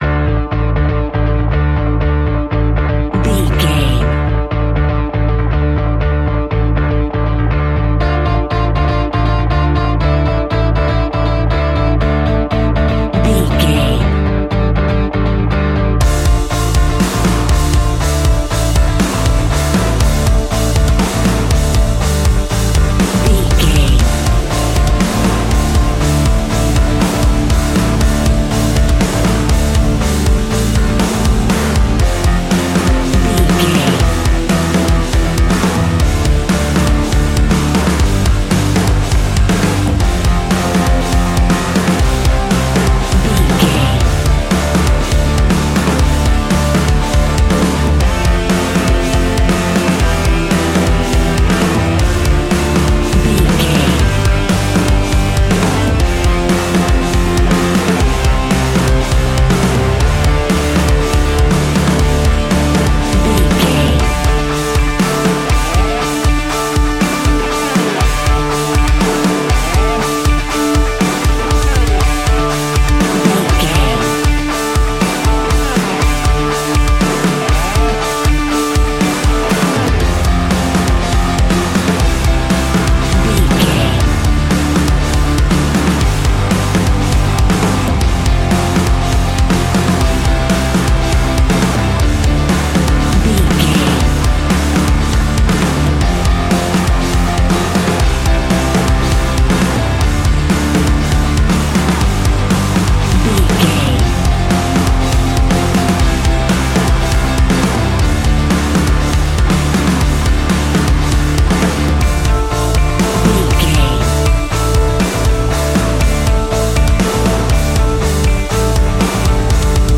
Ionian/Major
D♭
heavy rock
guitars
heavy metal
instrumentals